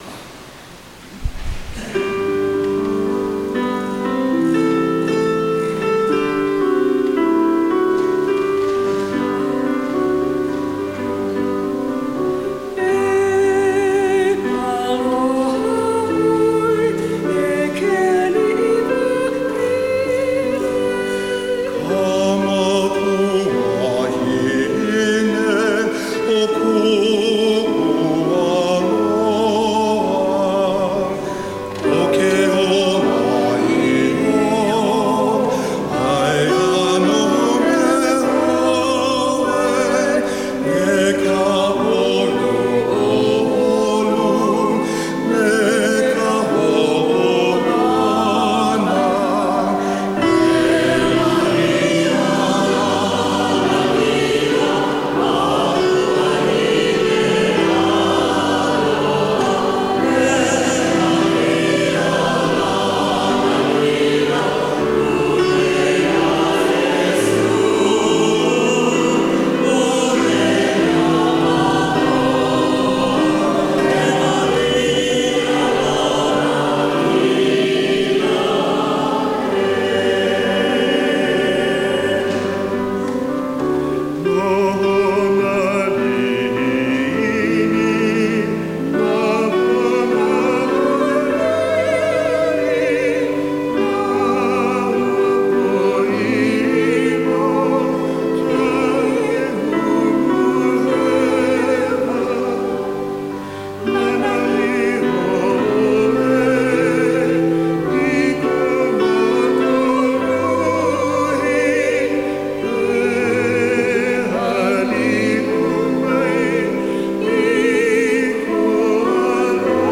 Instrumental | Downloadable
Soprano   Instrumental | Downloadable   Voice | Downloadable
Alto